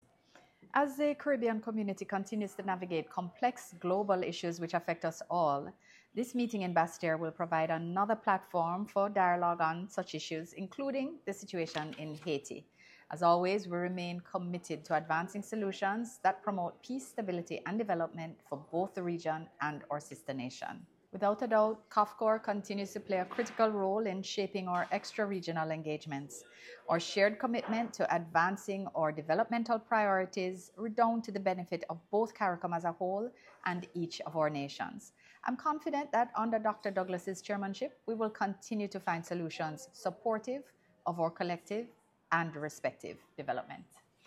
Minister-Speaks-on-Upcoming-COFCOR-Meeting.mp3